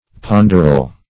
Search Result for " ponderal" : The Collaborative International Dictionary of English v.0.48: Ponderal \Pon"der*al\, a. [Cf. F. pond['e]ral.]